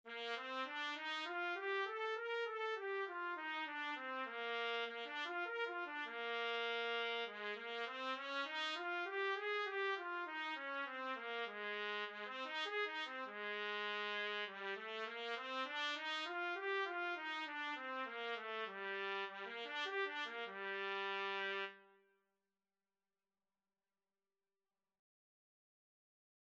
Trumpet scales and arpeggios - Grade 1
G4-Bb5
Bb major (Sounding Pitch) C major (Trumpet in Bb) (View more Bb major Music for Trumpet )
4/4 (View more 4/4 Music)
trumpet_scales_grade1.mp3